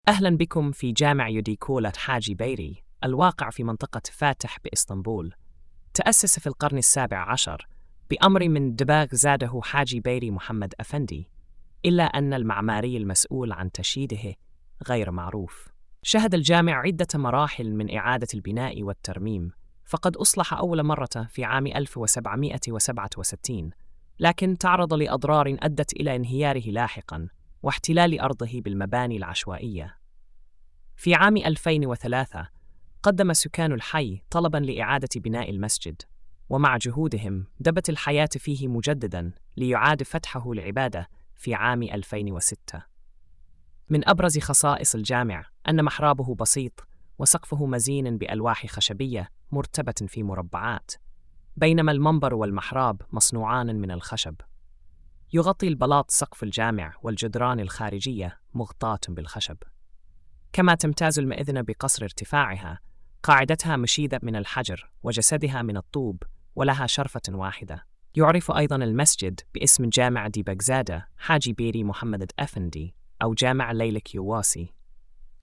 السرد الصوتي